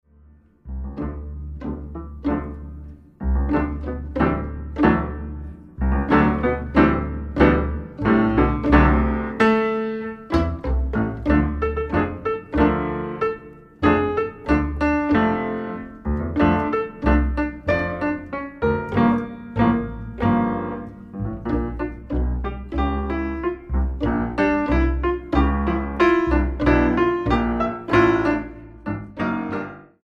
Bandoneón
cantante